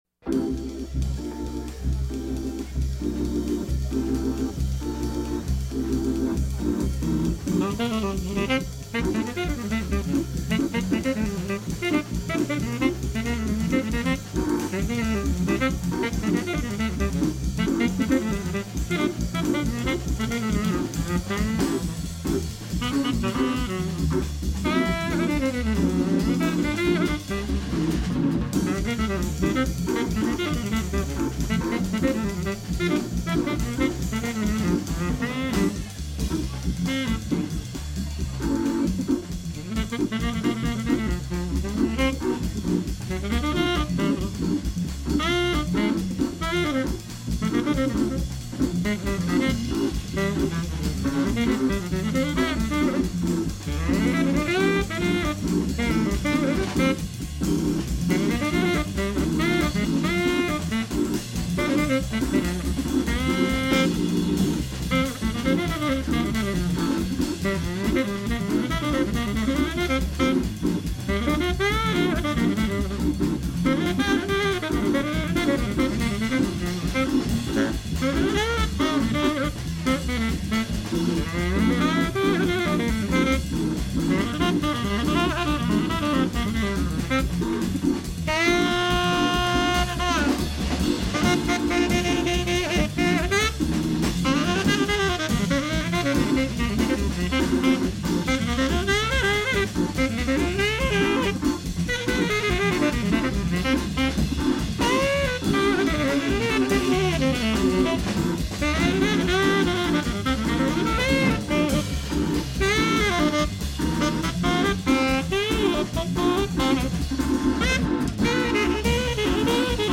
a 1950-60’s classic throwback
tenor sax
Hammond B-3 organ
drums